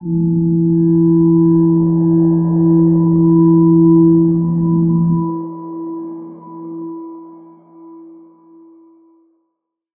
G_Crystal-E4-f.wav